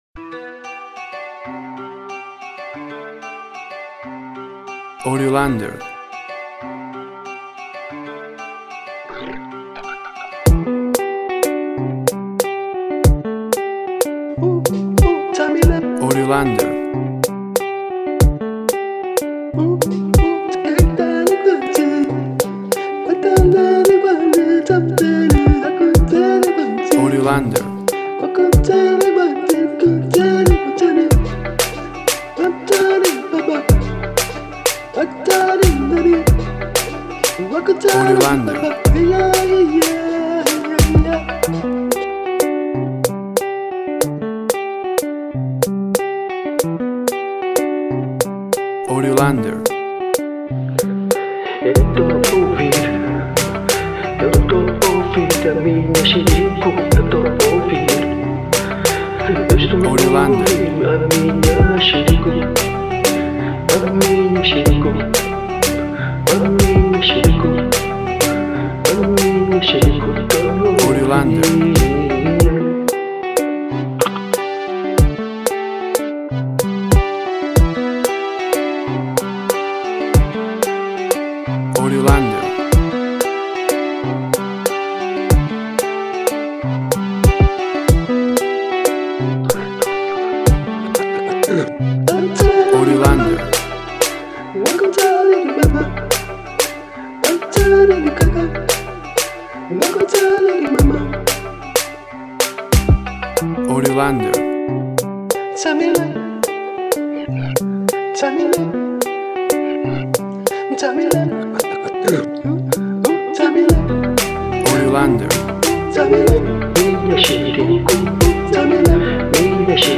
afropop song
Tempo (BPM): 93